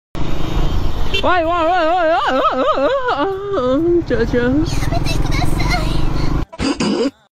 chacha ahh Meme Sound Effect
Category: Reactions Soundboard